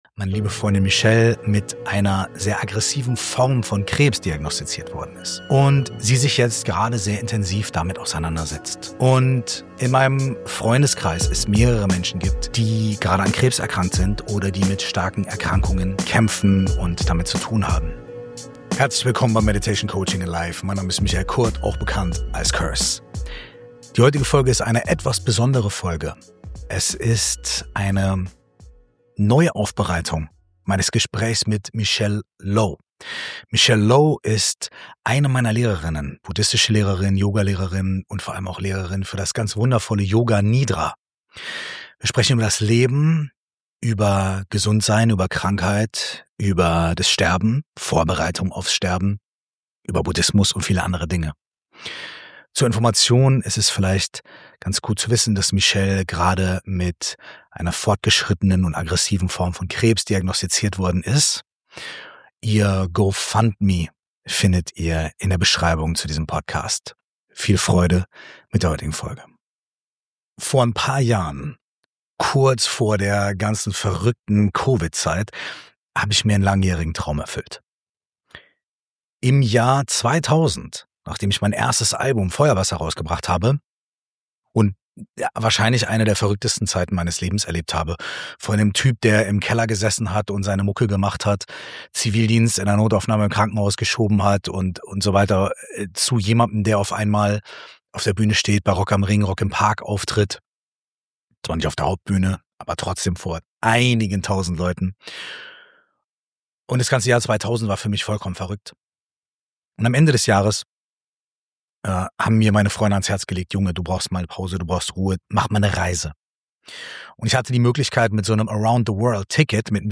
Diese Episode ist ein Remake eines früheren, sehr persönlichen Gesprächs – neu aufbereitet und aktueller denn je.